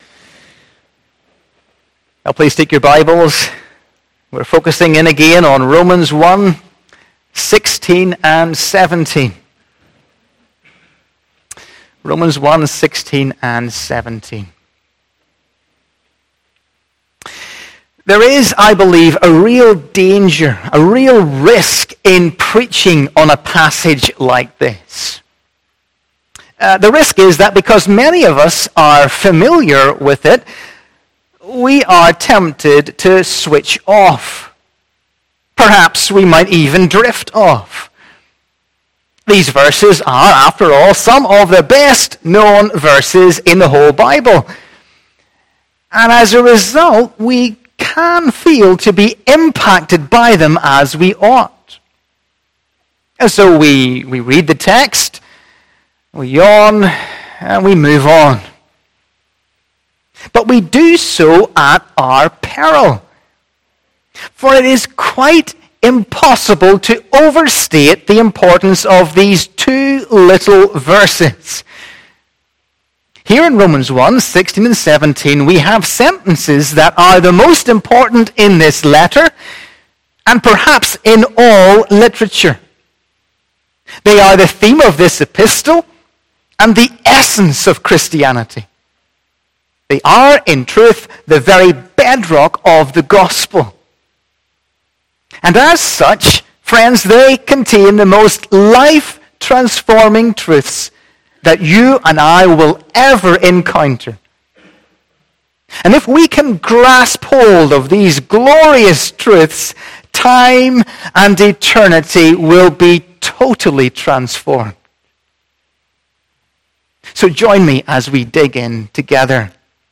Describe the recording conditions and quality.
Romans 1:16-17 Service Type: Morning Service Bible Text